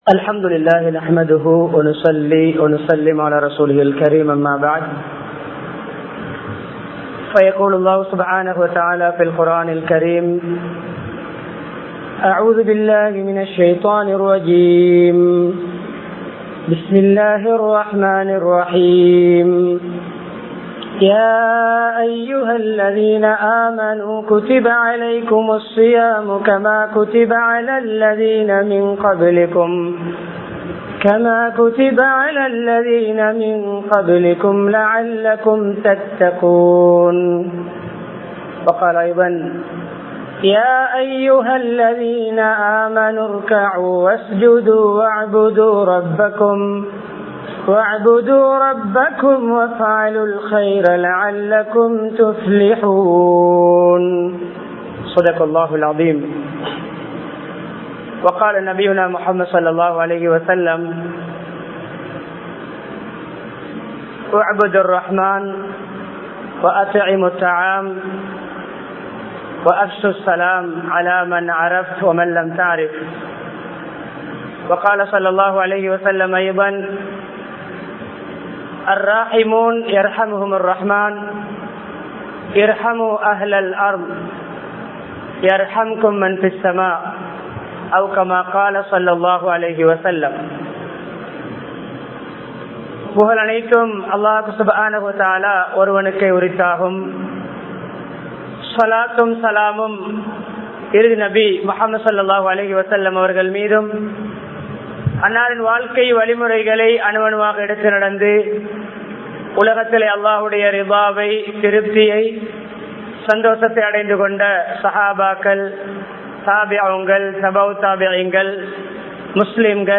அல்லாஹ்வின் அருளின் பெறுமதி | Audio Bayans | All Ceylon Muslim Youth Community | Addalaichenai
Dehiwela, Kawdana Road Jumua Masjidh